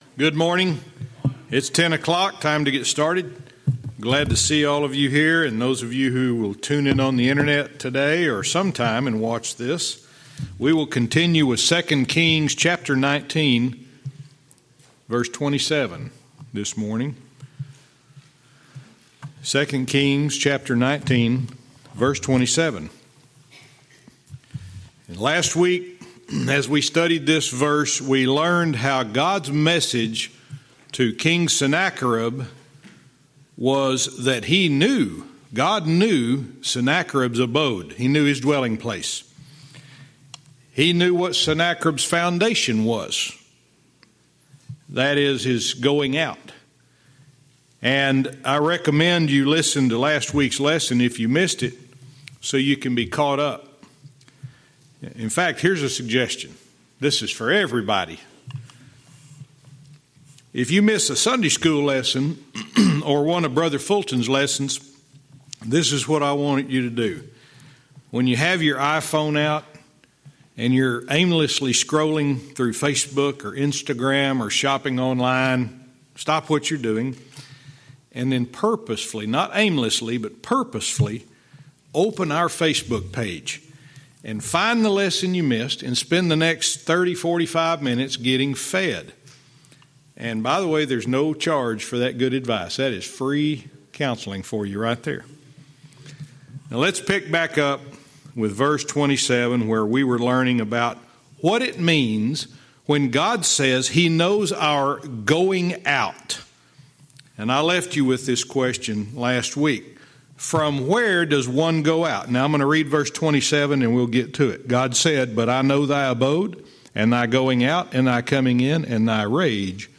Verse by verse teaching - 2 Kings 19:27-28